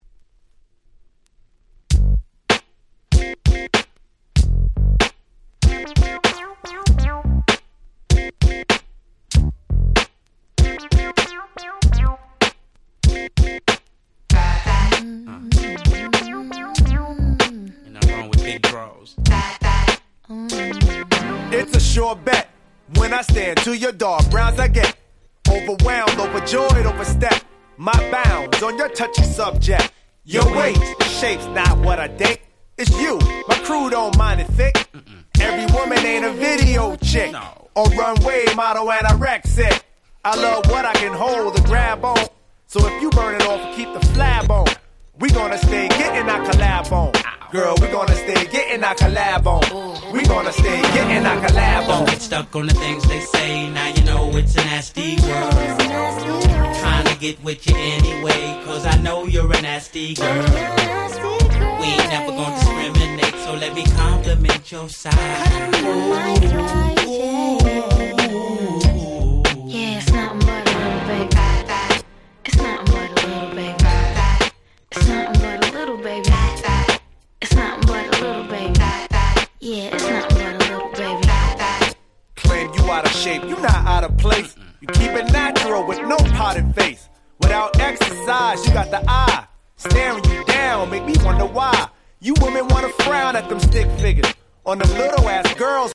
01' Super Nice Hip Hop !!